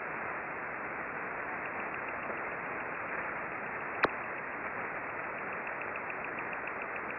We observed mostly S-bursts throughout the pass. There also was considerable interference from sweepers and other repetitive signals.